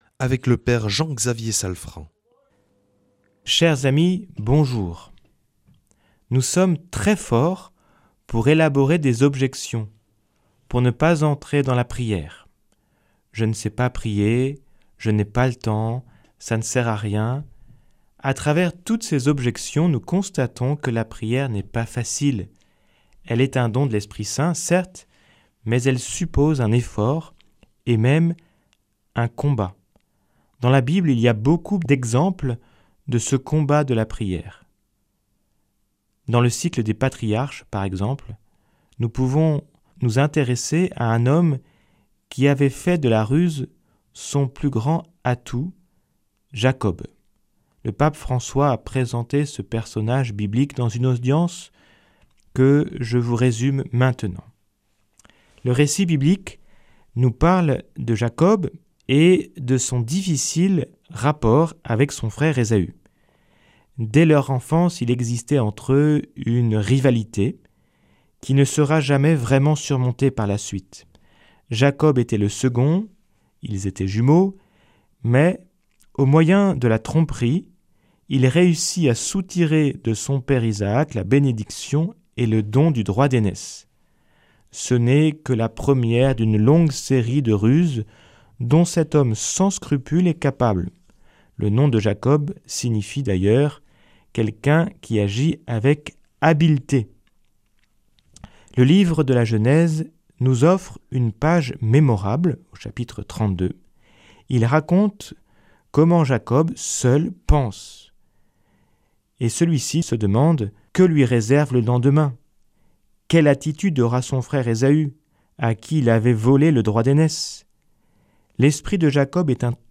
vendredi 9 janvier 2026 Enseignement Marial Durée 10 min